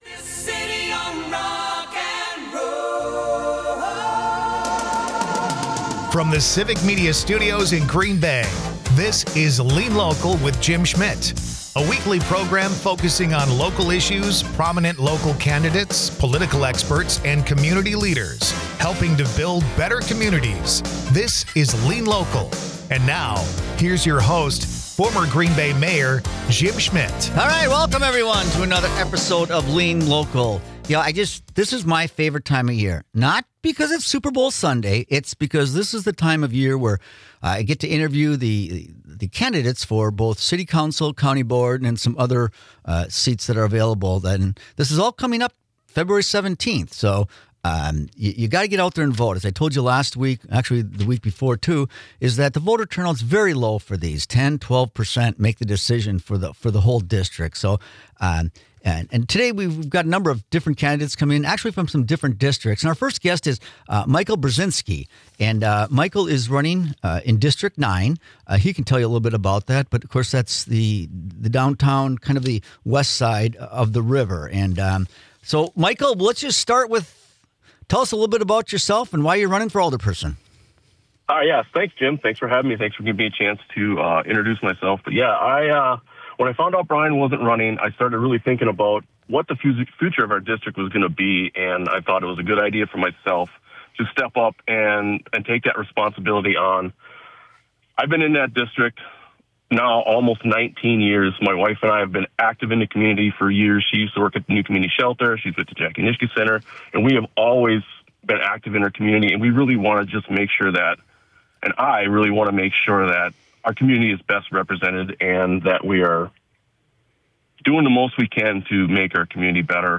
On this Super Bowl Sunday, former Green Bay Mayor Jim Schmitt interviews several more candidates running in these nonpartisan races:
Dive into the heart of community issues with 'Lean Local,' hosted by former Green Bay Mayor Jim Schmitt.